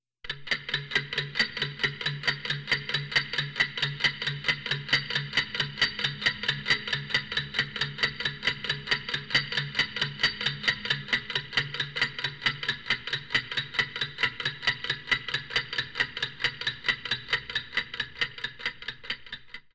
alarm-clock-sound